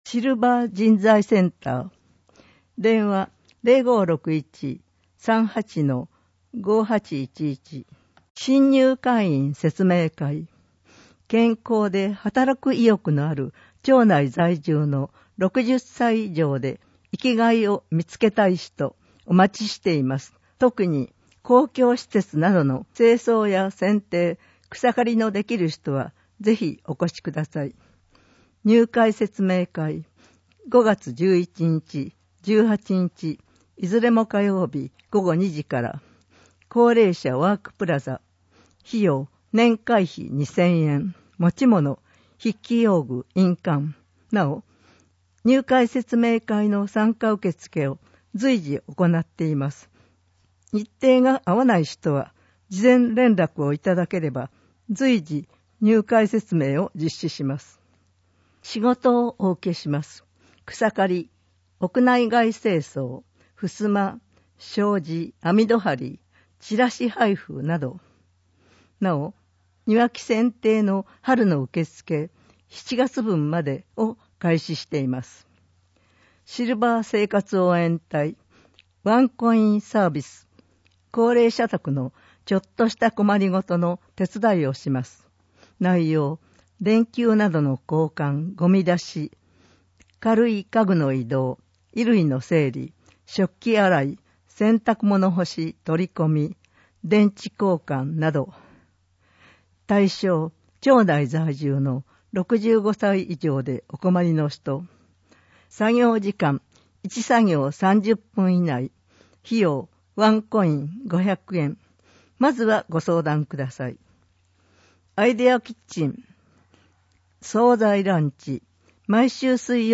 広報とうごう音訳版（2020年1月号）